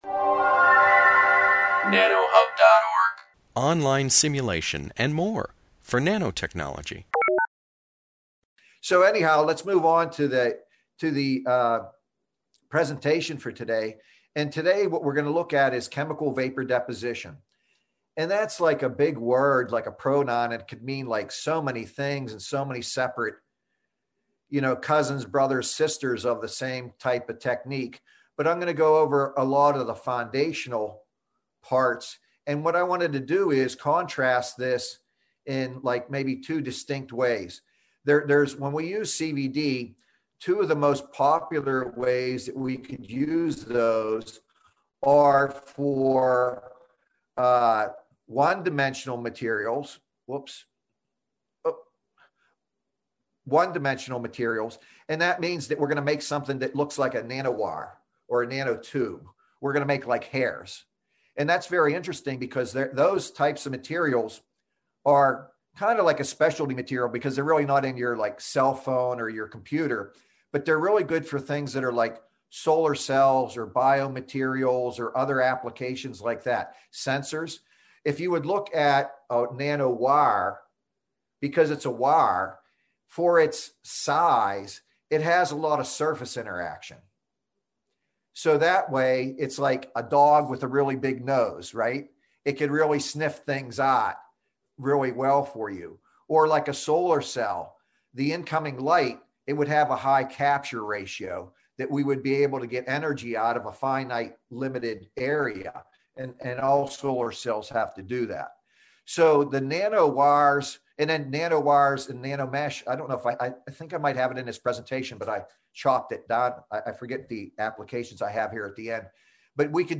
This webinar, published by the Nanotechnology Applications and Career Knowledge Support (NACK) Center at Pennsylvania State University, focuses on chemical vapor deposition (CVD).